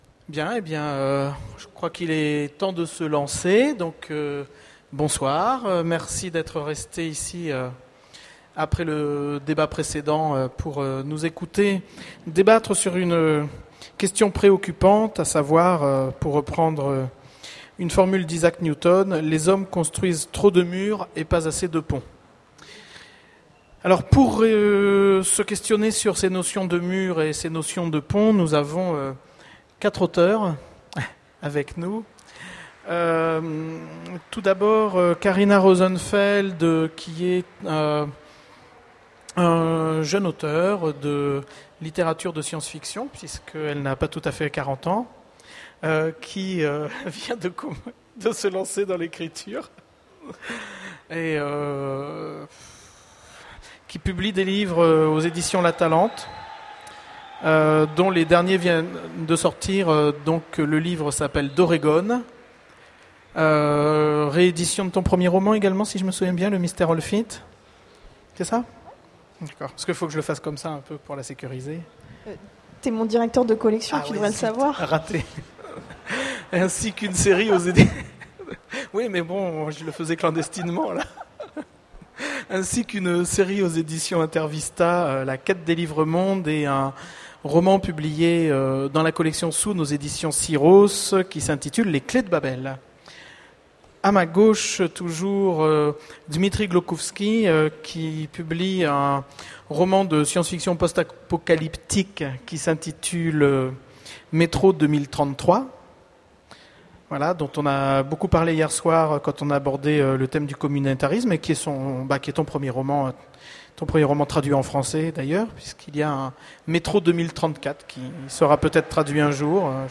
Utopiales 2010 : Conférence La construction des murs
Voici l'enregistrement de la conférence " La construction des murs " aux Utopiales 2010.